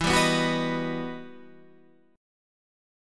E Minor Major 9th